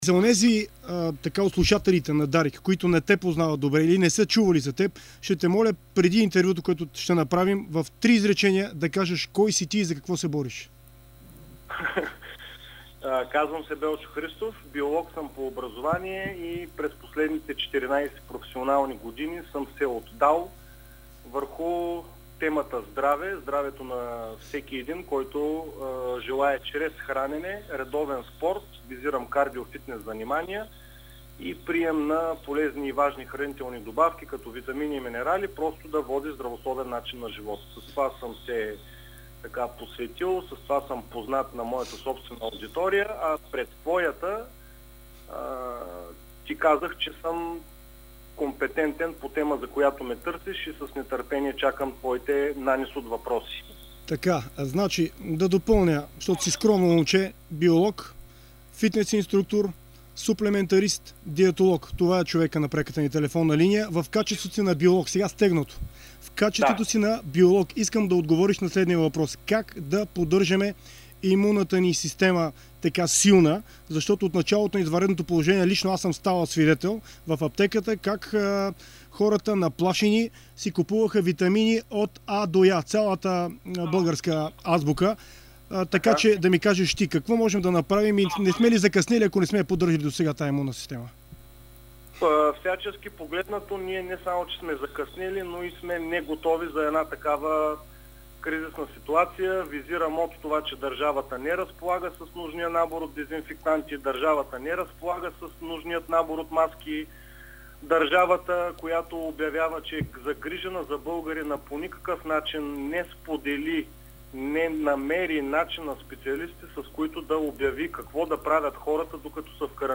Специалистът говори в интервю за Дарик радио и dsport за това как да поддържаме имунната си система силна, на какъв тип храни да заложим и какви да изключим. Той сподели и как, и в каква честота да се тренира, макар и в домашни условия.